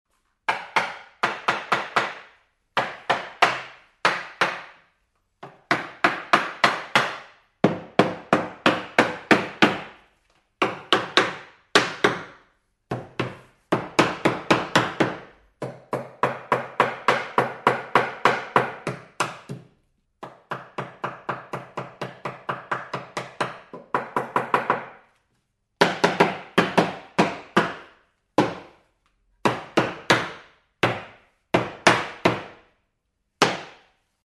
На этой странице собраны разнообразные звуки гвоздя: забивание, удары по металлу, скрип и другие эффекты.
Стук молотка с насадкой по деревянным рейкам